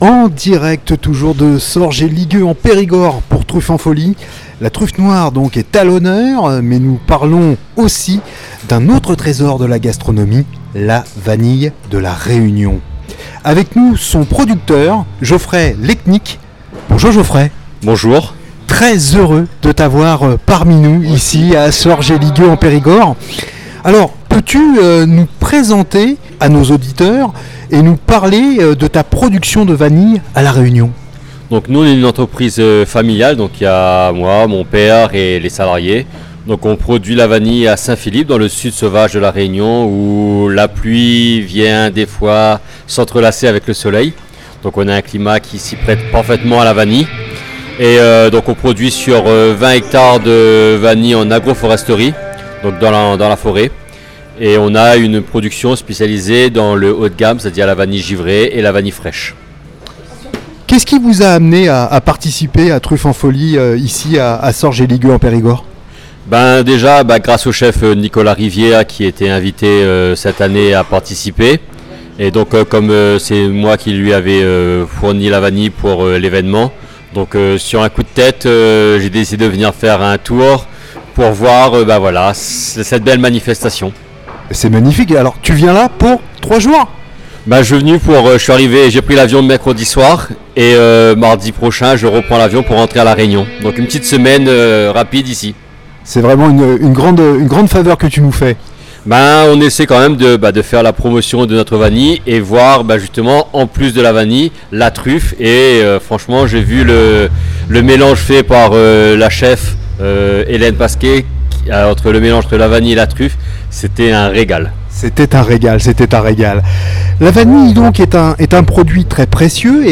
En direct de Sorges-et-Ligueux-en-Périgord pour “ Truffes en Folie 2026 ”.